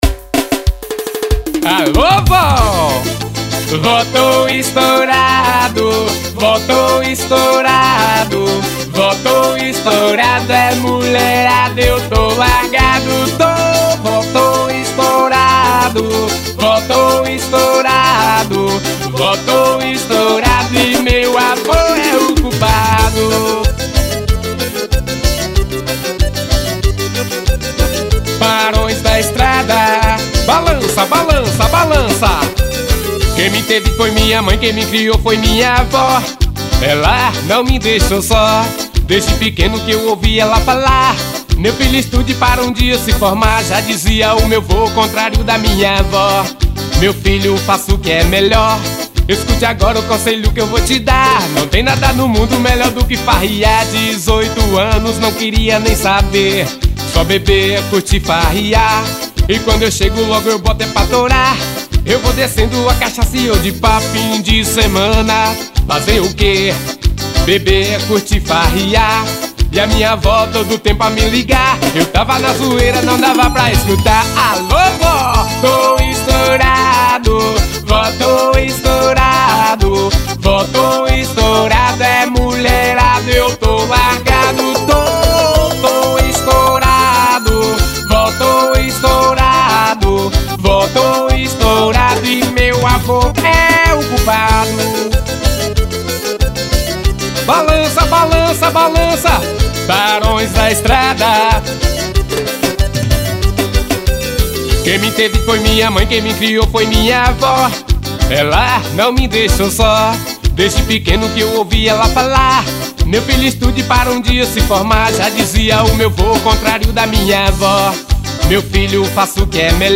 Forró safado.